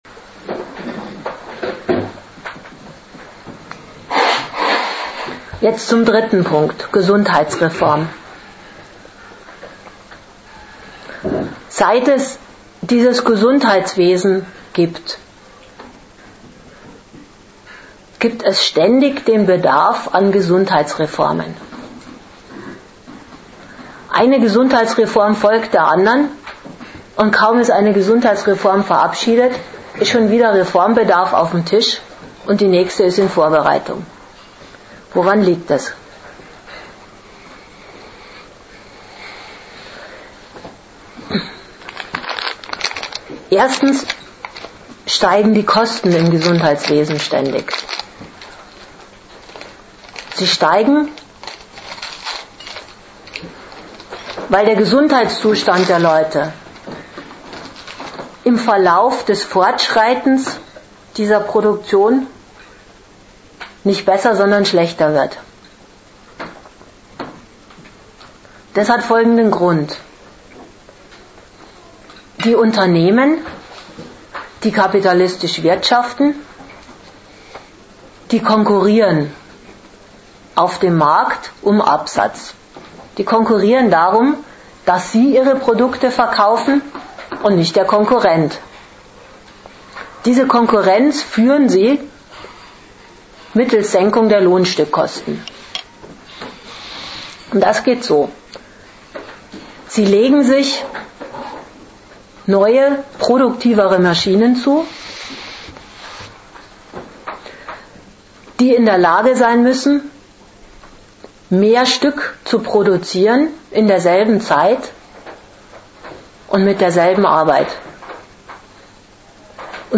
Datum 11.06.2006 Ort Regensburg Themenbereich Arbeit, Kapital und Sozialstaat Veranstalter Forum Kritik Dozent Gastreferenten der Zeitschrift GegenStandpunkt Volksseuchen gehören in unseren Breiten der Vergangenheit an.